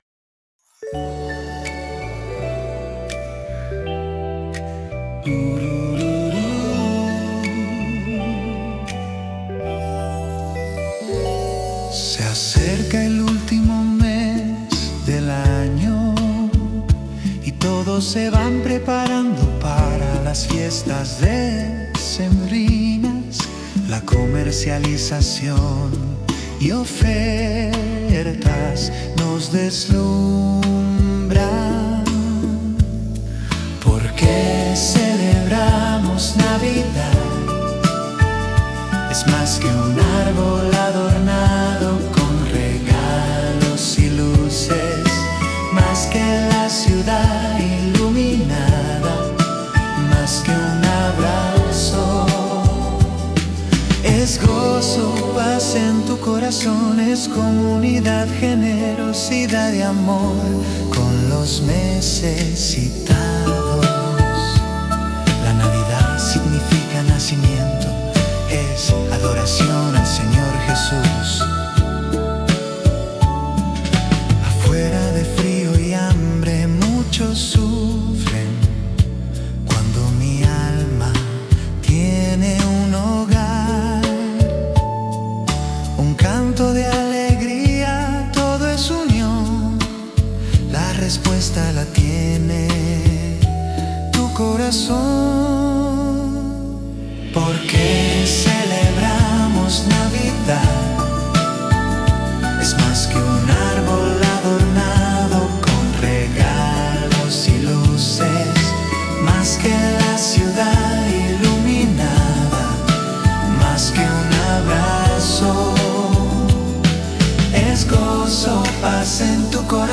Campanillas